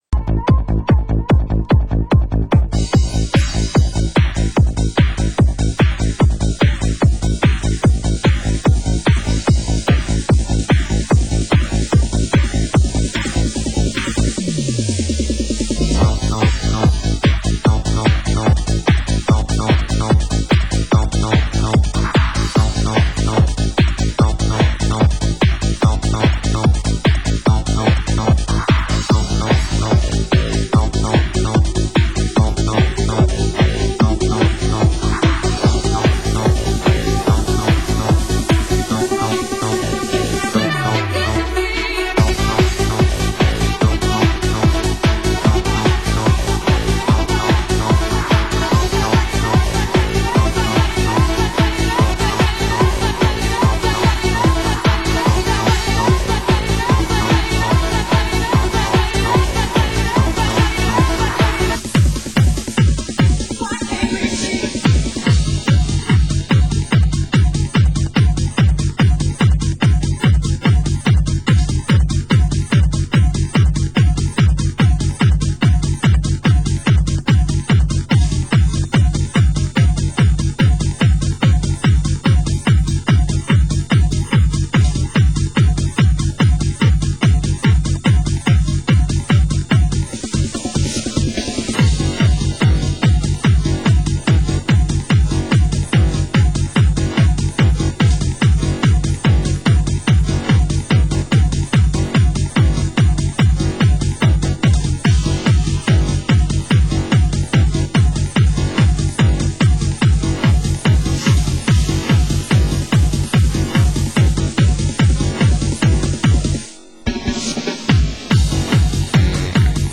Genre Hard House